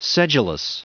Prononciation du mot sedulous en anglais (fichier audio)
Prononciation du mot : sedulous